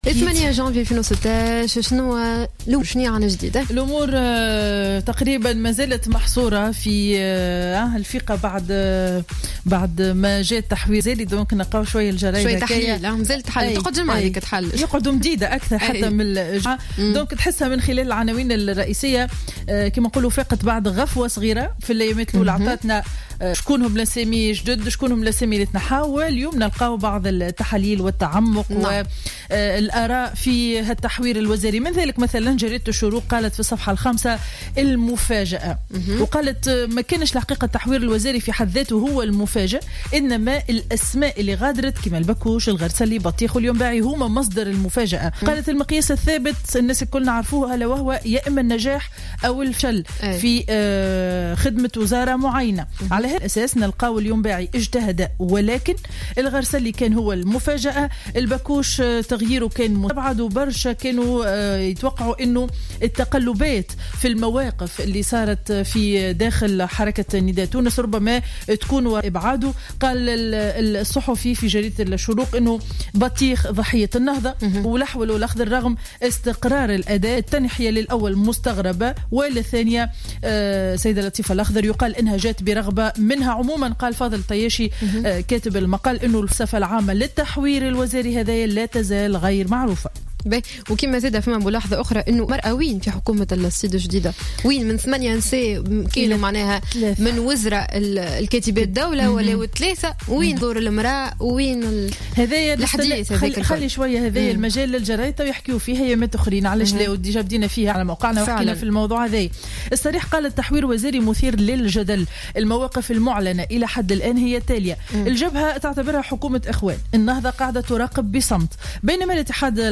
Revue de presse 08/01/2016 à 09:47